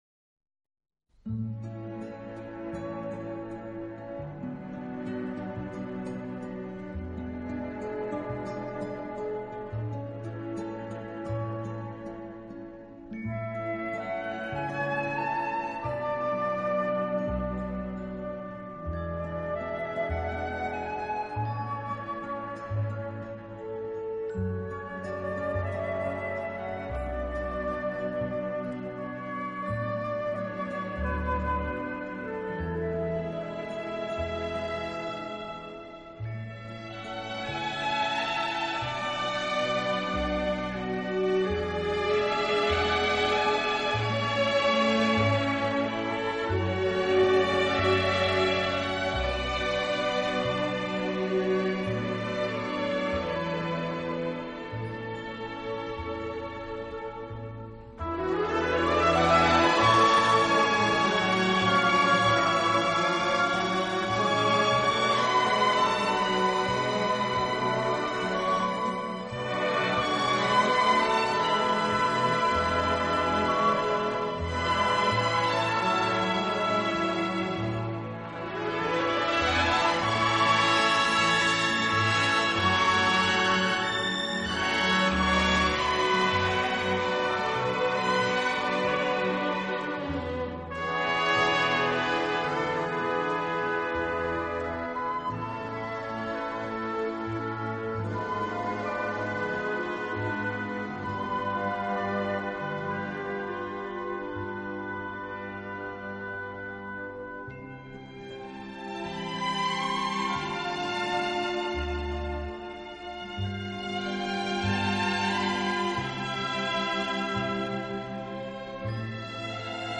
【轻音乐】
这个乐团的演奏风格流畅舒展，
旋律优美、动听，音响华丽丰满。